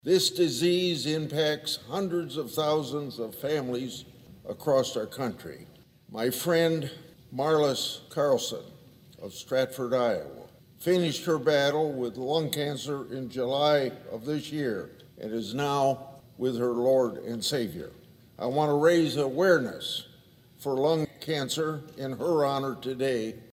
Senator Chuck Grassley used his floor remarks on Wednesday to raise awareness. He said one in 18 people receive a lung cancer diagnosis during their lifetime.